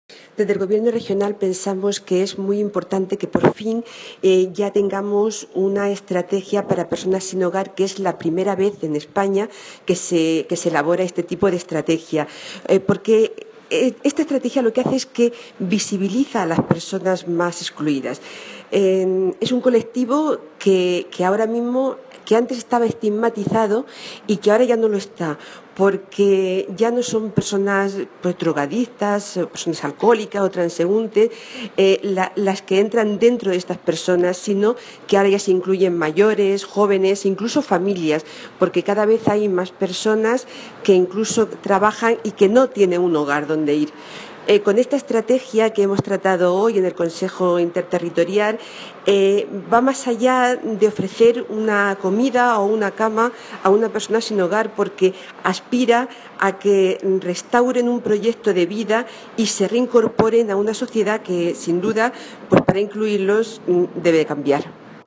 Declaraciones de la consejera de Familia e Igualdad de Oportunidades, Violante Tomás, tras el Pleno del Consejo Territorial de Asuntos Sociales